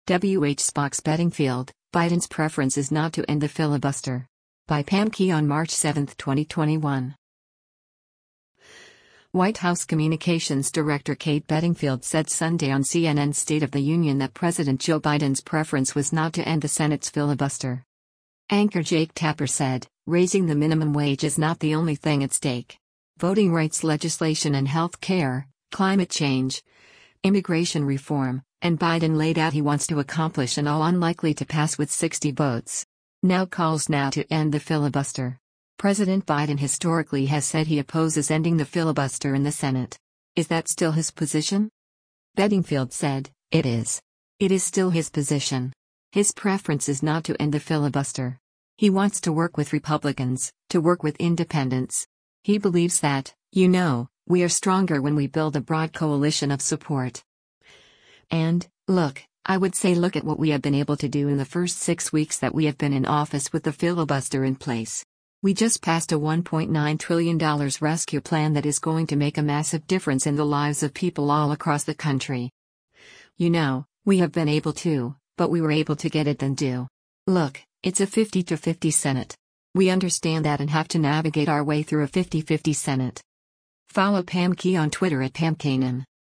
White House communications director Kate Bedingfield said Sunday on CNN’s “State of the Union” that President Joe Biden’s preference was not to end the Senate’s filibuster.